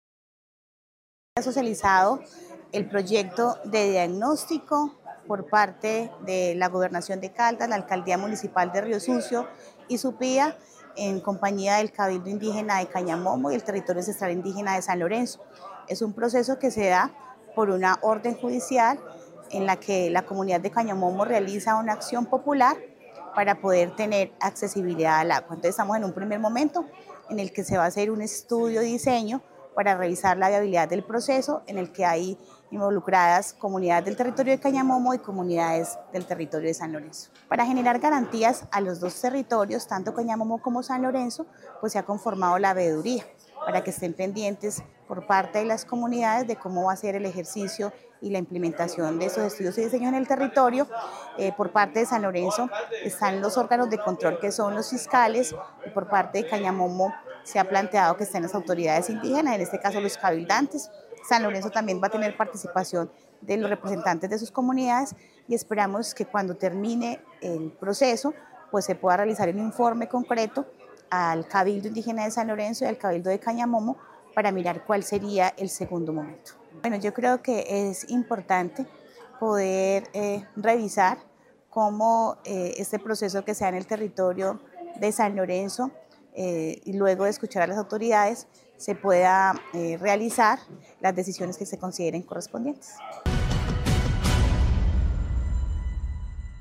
Gobernadora Indígena del Resguardo San Lorenzo, Sori Mildonia Morales.